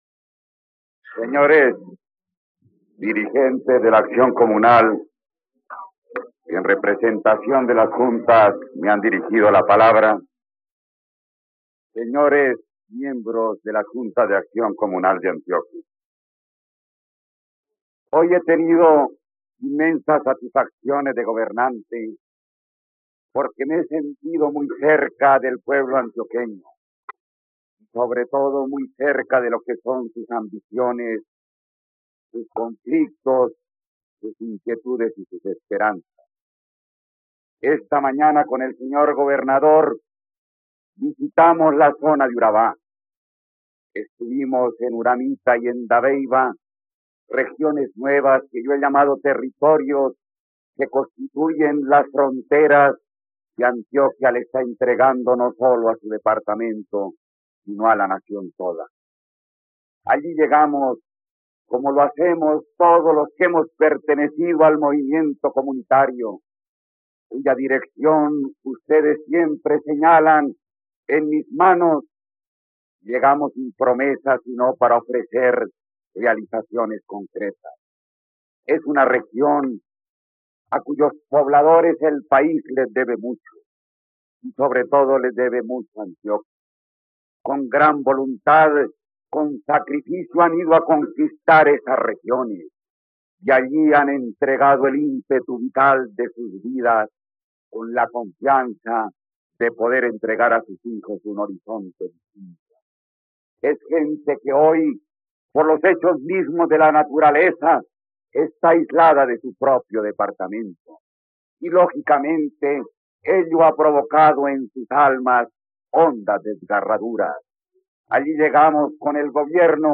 ..Escucha ahora el discurso de Misael Pastrana ante la Junta de Acción Comunal de Antioquia, el 17 de febrero de 1971, en la plataforma de streaming RTVCPlay.